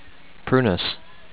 PRU-nus